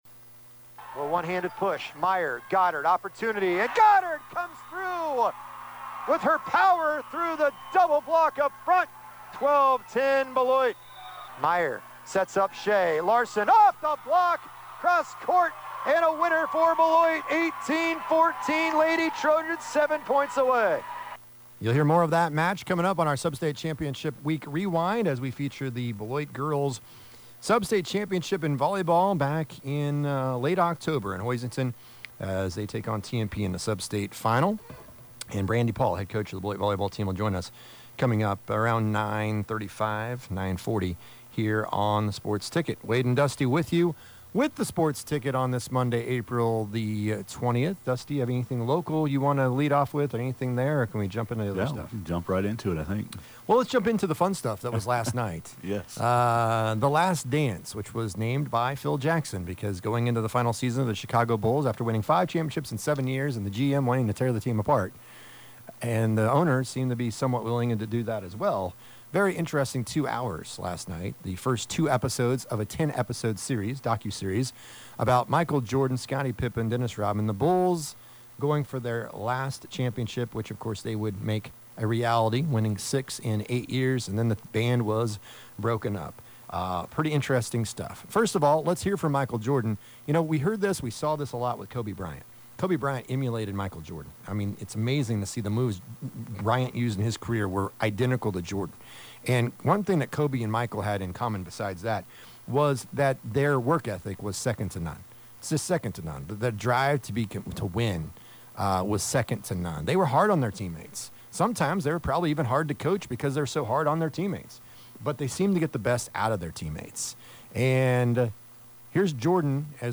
Bruce Weber joins us to talk about K-State Men's Basketball
We replay the 2nd set of the 2019 3A Sub-State Final: Beloit vs TMP